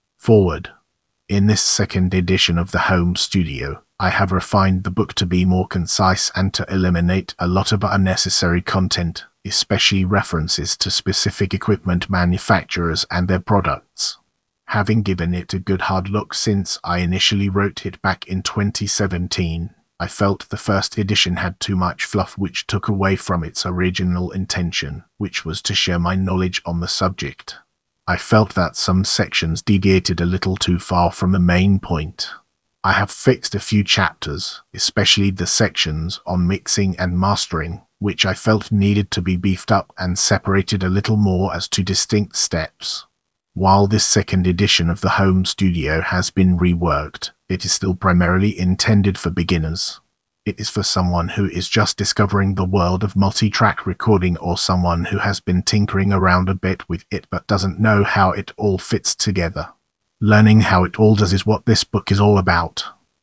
The Home Studio – Audiobook